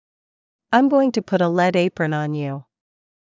ｱｲﾑ ｺﾞｰｲﾝｸﾞ ﾄｩ ﾌﾟｯﾄ ｱ ﾚｯﾄﾞ ｴｲﾌﾟﾛﾝ ｵﾝ ﾕｰ